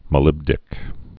(mə-lĭbdĭk)